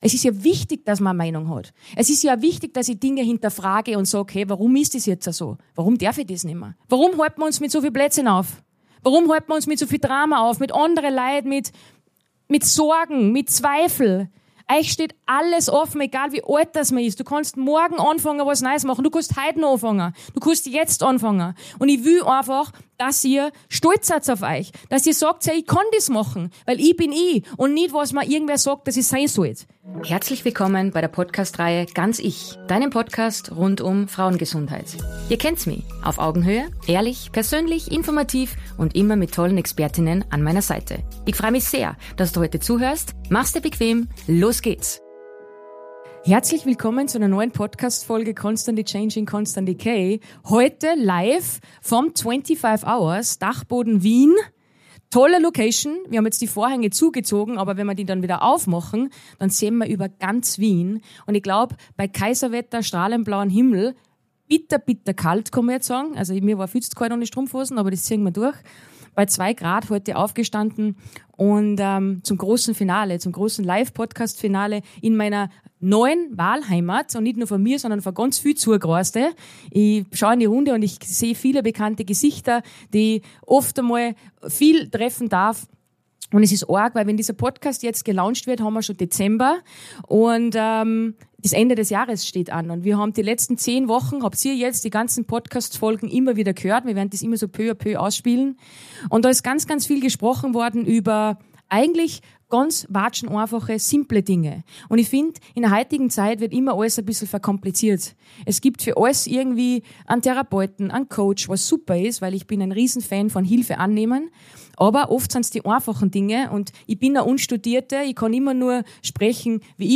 Zum Schluss holen wir das gesamte Team auf die Bühne, sprechen über Dankbarkeit, Zusammenhalt und warum diese Tour so viel mehr war als nur ein Podcast. Ein echtes, lautes, herzliches Finale – so wie wir sind.